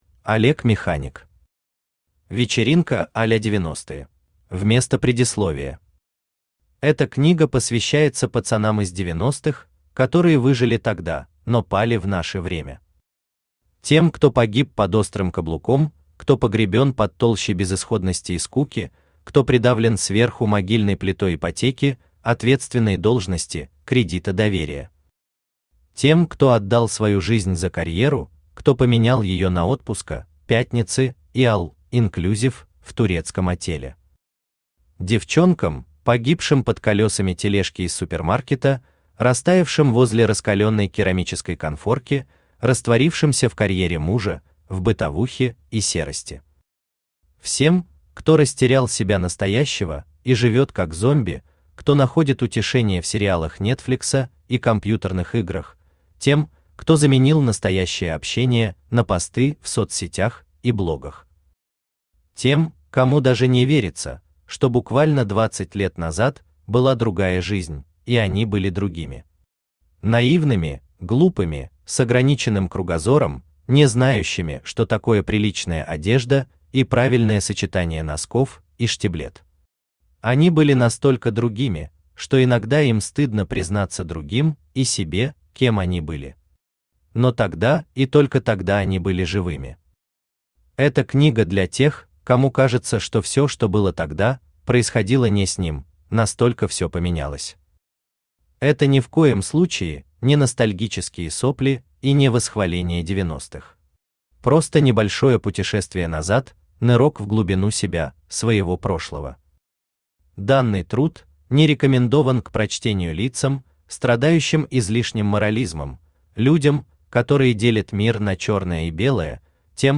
Аудиокнига Вечеринка а-ля 90-е | Библиотека аудиокниг
Aудиокнига Вечеринка а-ля 90-е Автор Олег Механик Читает аудиокнигу Авточтец ЛитРес.